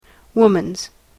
/ˈwʊmʌnz(米国英語)/